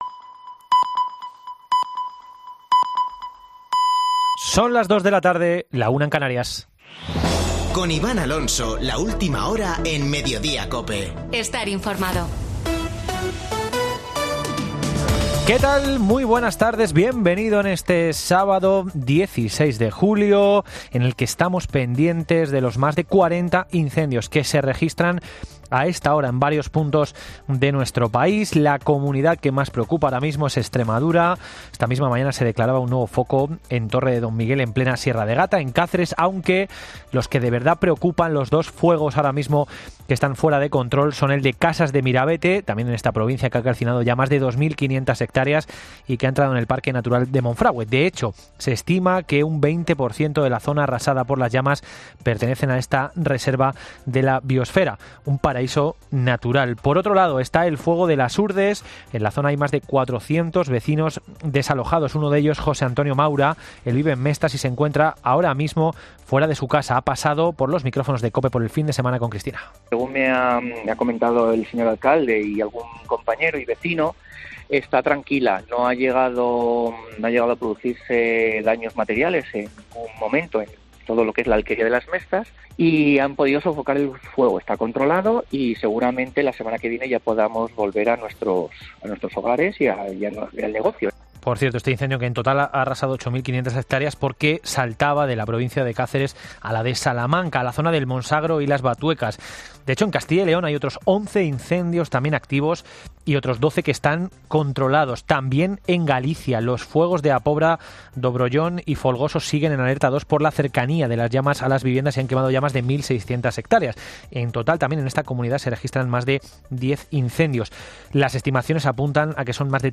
Boletín de noticias de COPE del 16 de julio de 2022 a las 14:00 horas